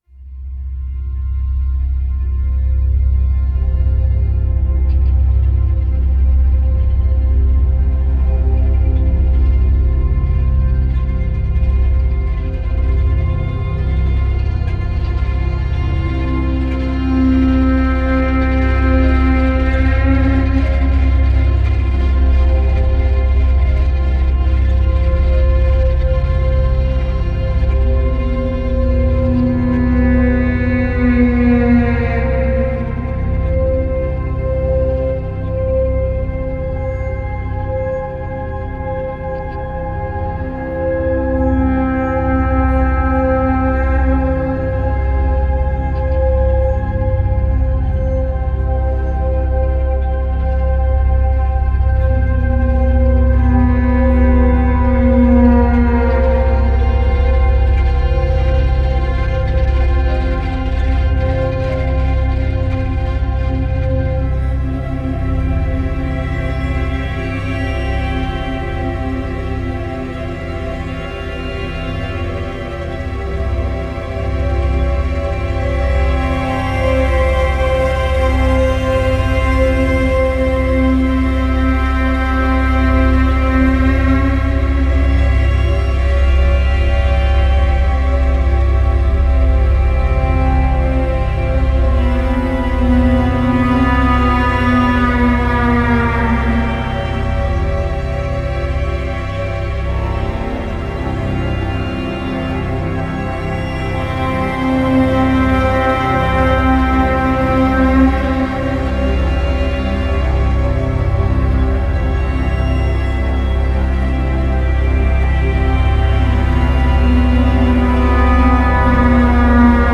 Anxious strings, wailing synth and dark drone intensify.